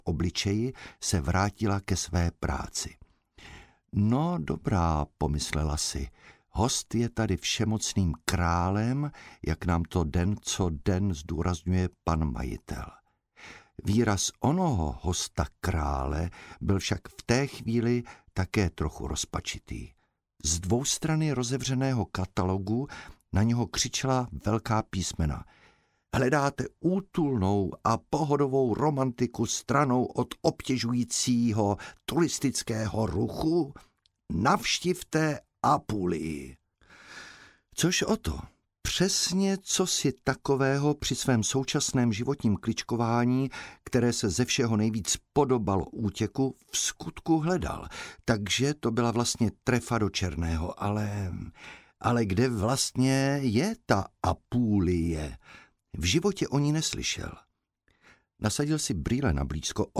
Audiobook
Read: Igor Bareš